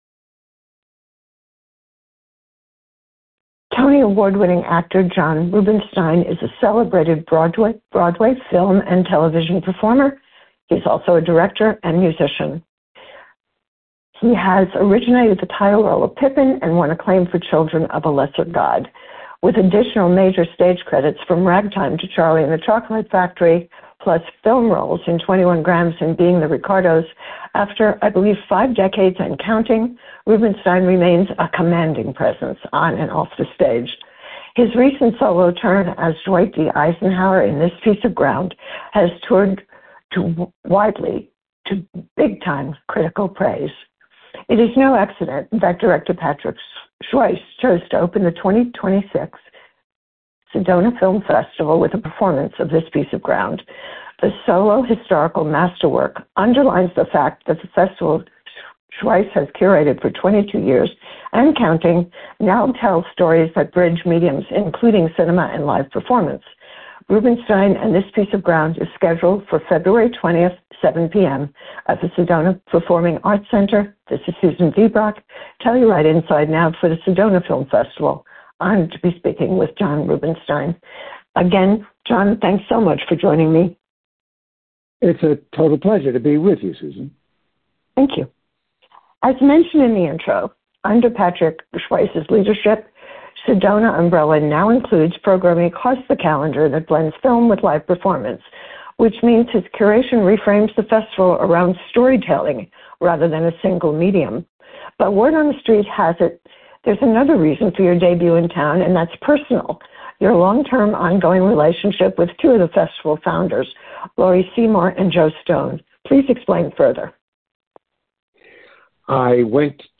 Go here to check out the full festival schedule and scroll down to listen to a podcast featuring stage, screen and TV actor, director composer John Rubinstein.